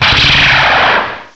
sovereignx/sound/direct_sound_samples/cries/zoroark.aif at master